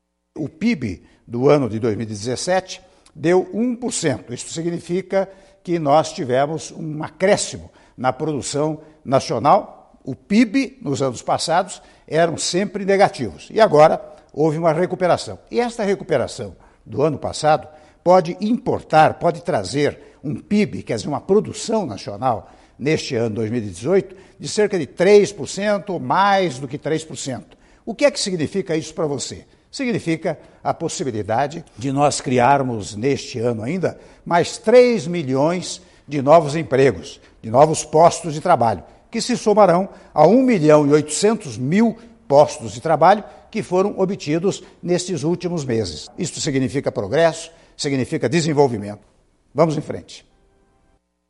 Pronunciamentos
Presidente Michel Temer - Resultado do PIB